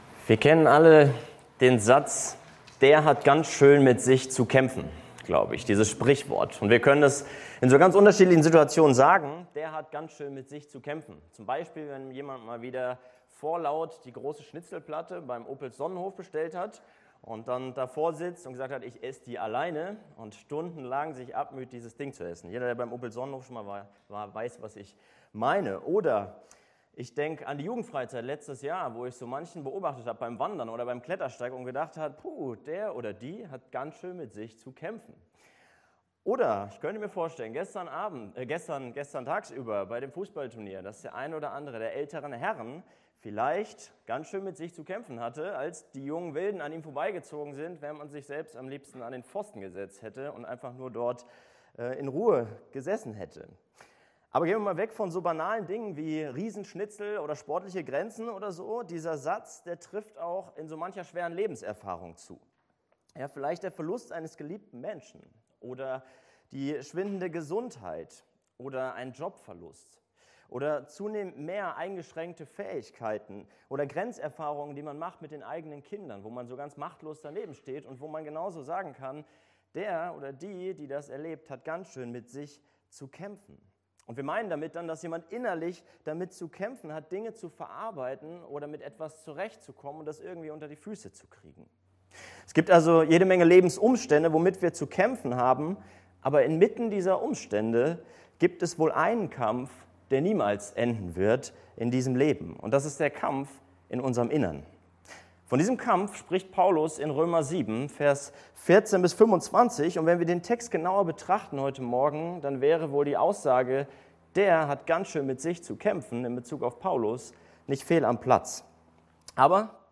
Die Gliederung der Predigt ist: Der lebenslange Kampf in mir: Der lästige Mitbewohner Der innerliche Zwiespalt Der siegreiche Befreier HausKreisLeitfaden Aufnahme (MP3) 39 MB PDF 457 kB Zurück Wozu das Gesetz?